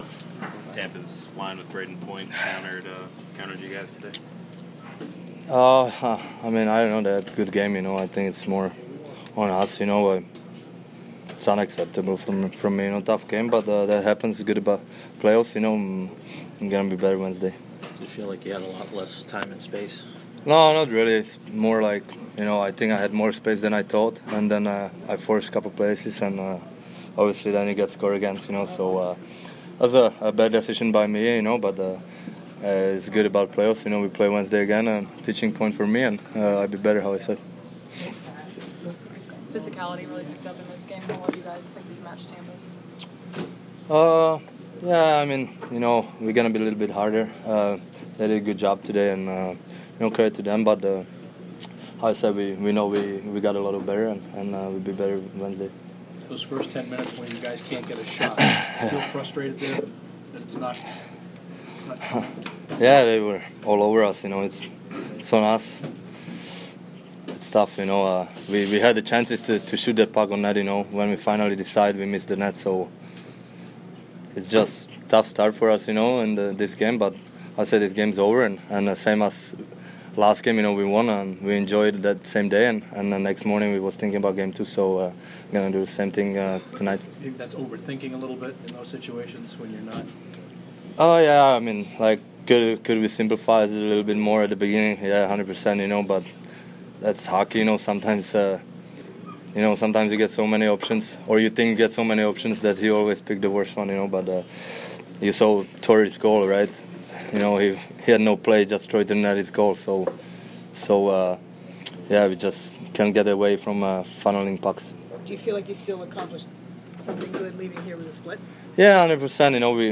David Pastrnak post-game 4/30